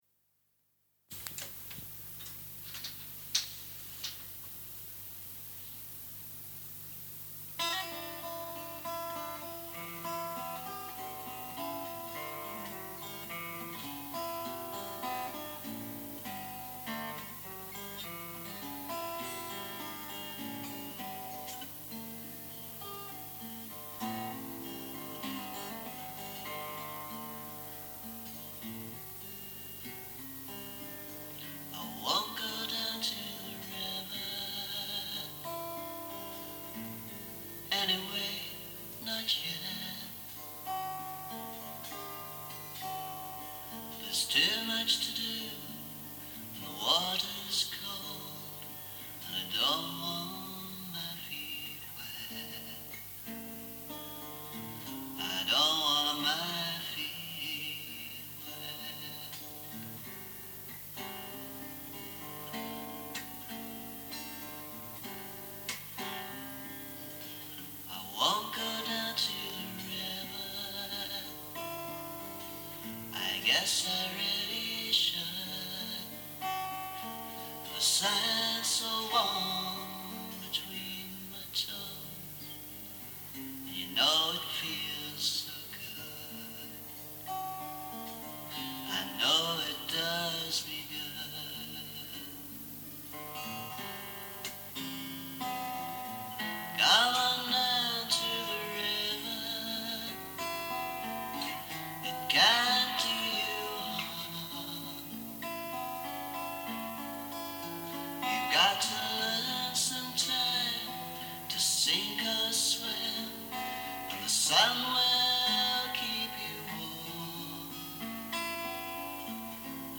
The present demo, however, was recorded back in the 80s, and the voice was in better shape.